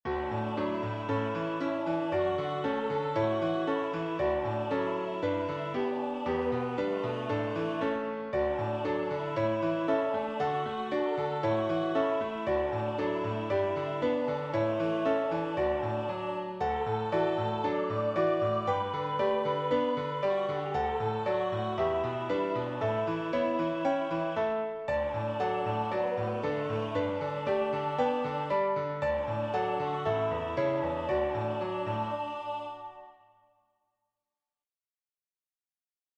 Celtic-style harvest hymn